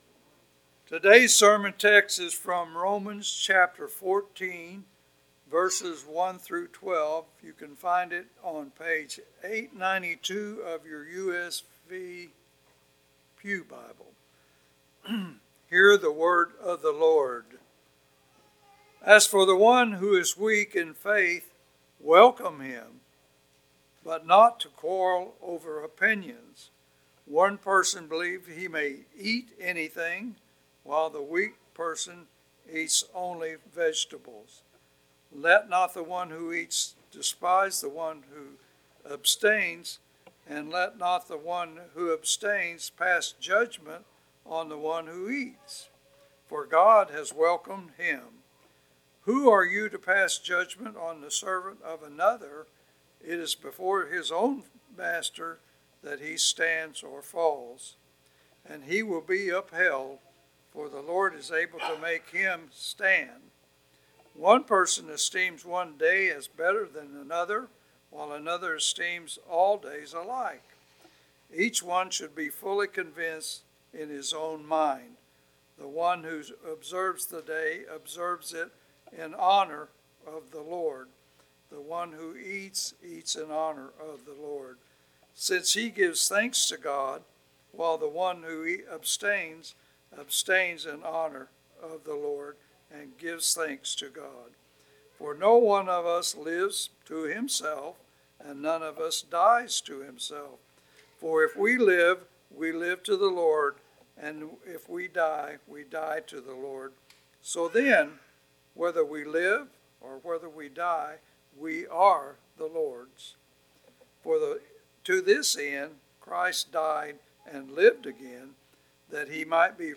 Passage: Romans 14:1-12 Service Type: Sunday Morning